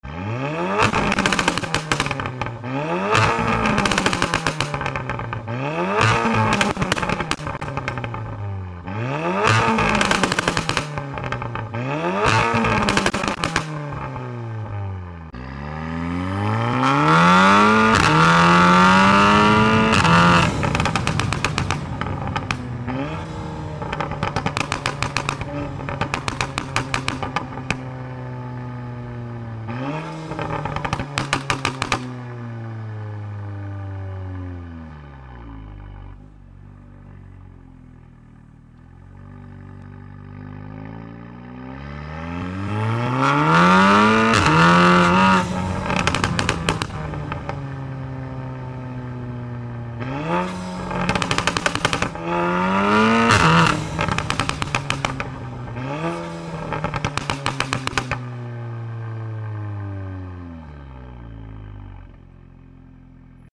Golf R pops and bangs sound effects free download